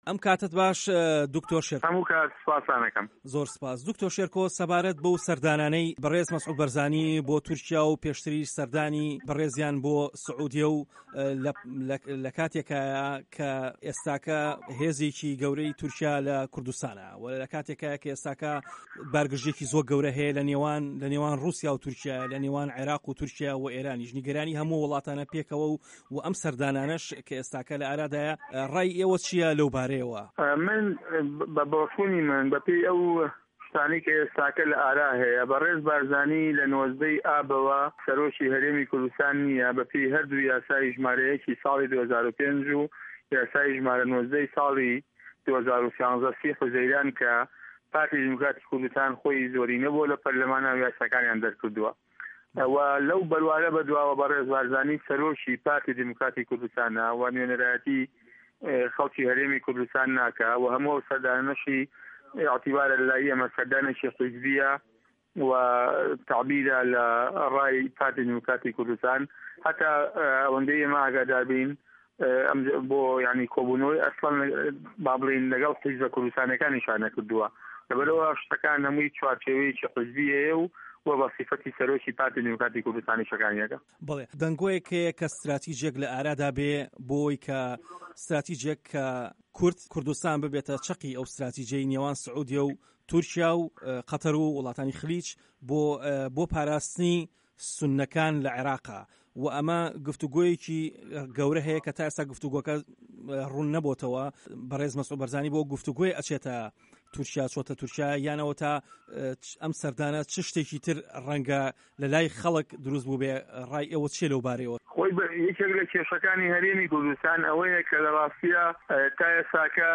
دەنگی ئەمریکا سەبارەت بە سەردانەکانی سەرۆکی هەرێم مەسعود بارزانی بۆ سعودیە و تورکیا وتوێژێکی لەگەڵ پەرلەمانتار دکتۆر شێرکۆ حەمە ئەمین قادر لەسەر لیستی گۆڕان سازکردووە.
وتووێژ لەگەڵ دکتۆر شێرکۆ حەمە ئەمین